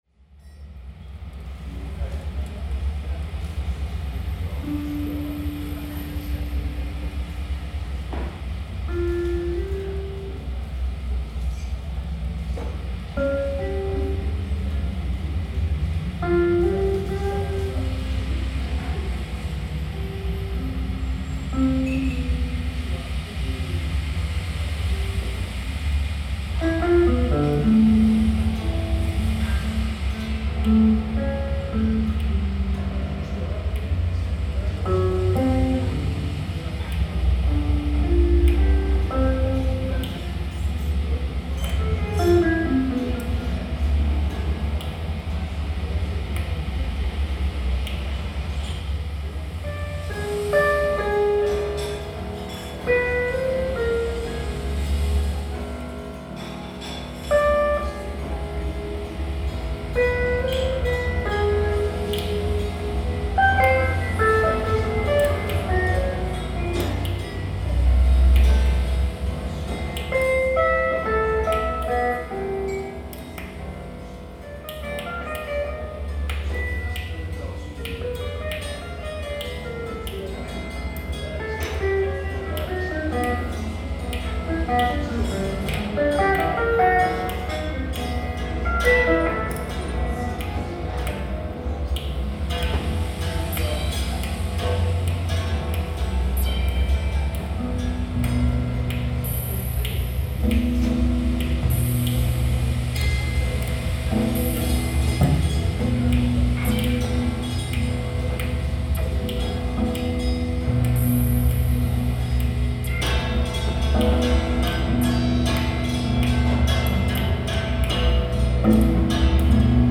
free improvisation
improvised music